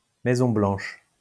Maison Blanche (French pronunciation: [mɛzɔ̃ blɑ̃ʃ]